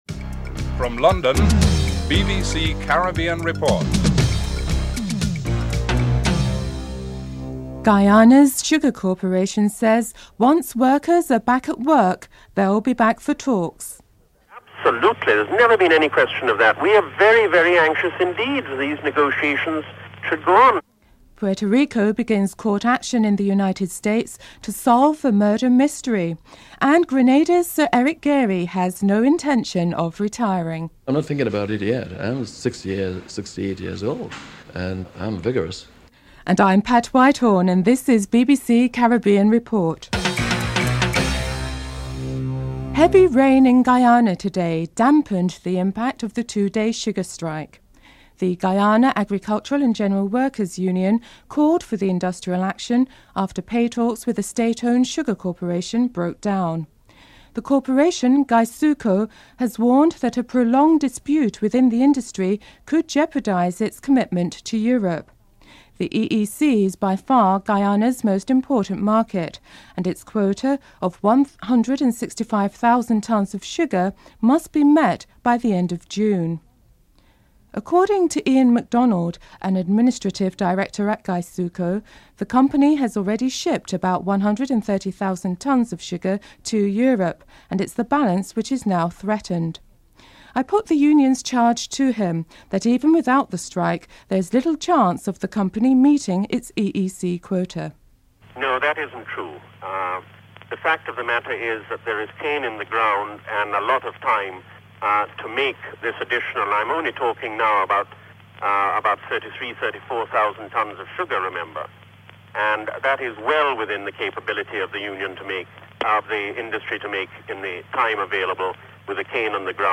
Report ends abruptly.